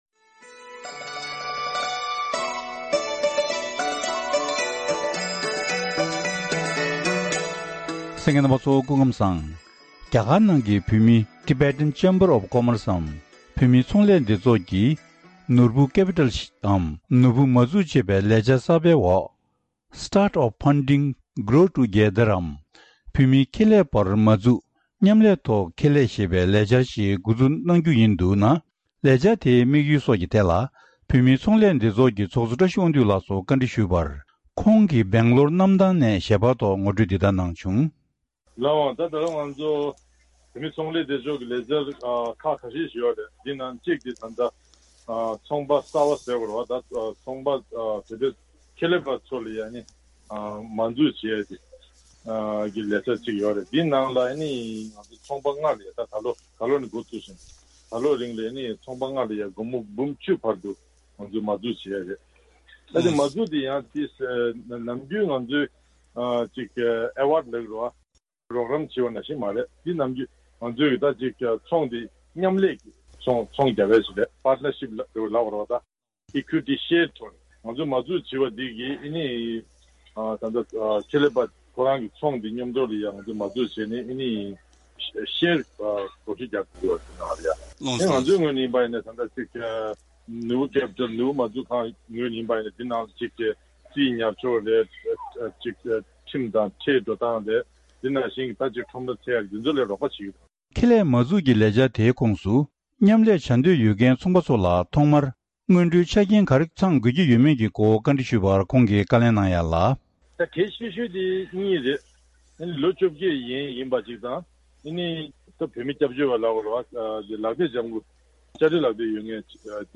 འདྲི་ཞུས་ནས་ཕྱོགས་སྒྲིགས་ཞུས་པ་ཞིག་གསན་རོགས་གནང་།